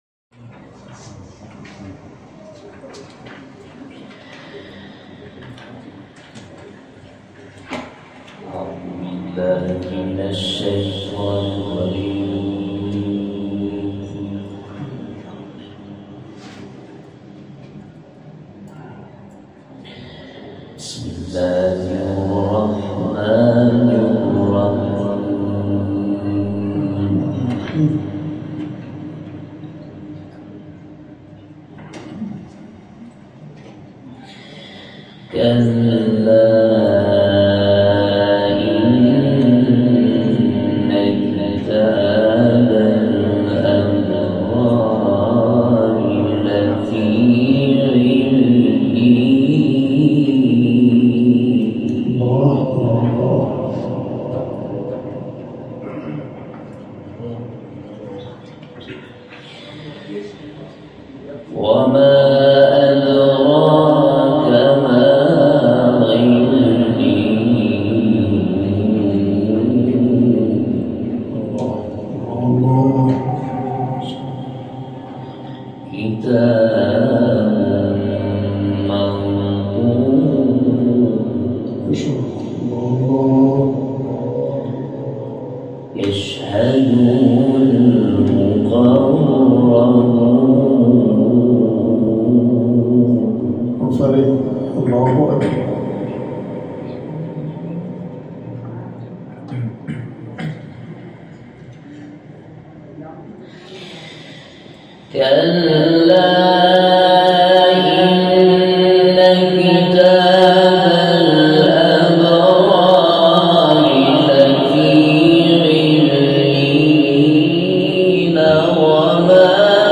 تلاوت سوره مطففین